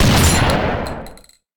rifle.ogg